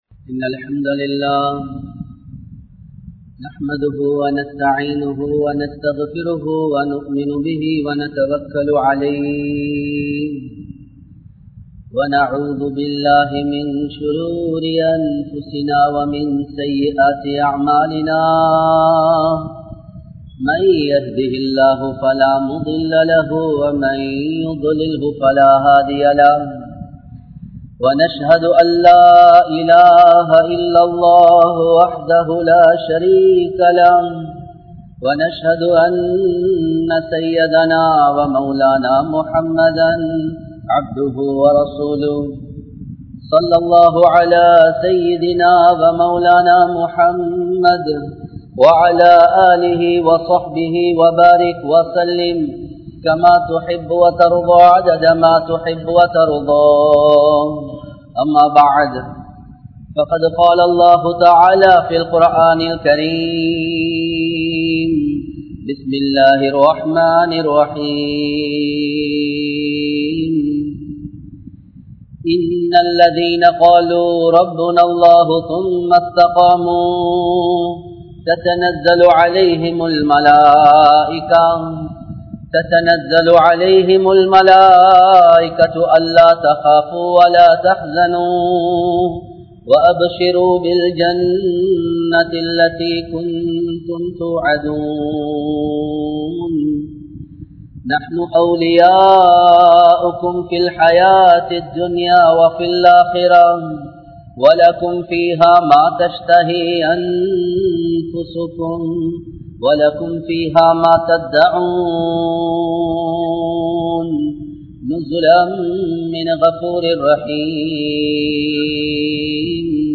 Kootu Zakath(கூட்டு ஸகாத்) | Audio Bayans | All Ceylon Muslim Youth Community | Addalaichenai
Colombo 12, Aluthkade, Muhiyadeen Jumua Masjidh